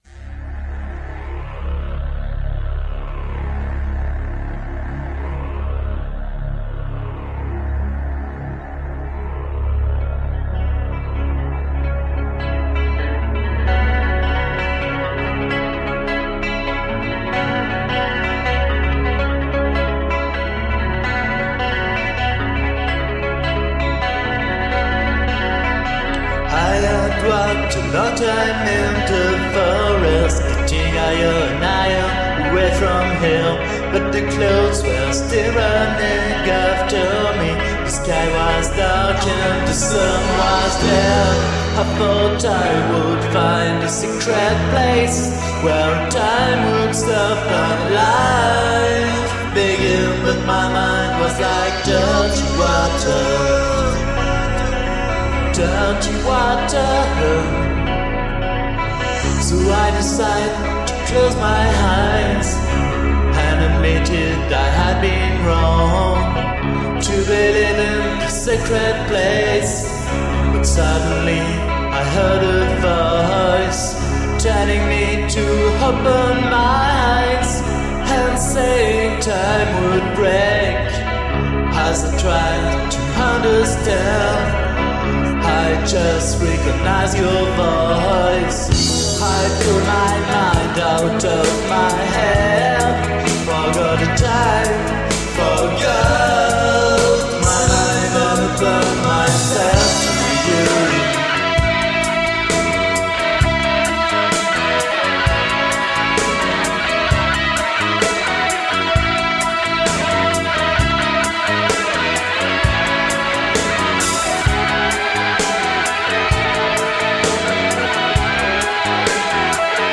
Bass/Vocals
guitar/keyboards
Drums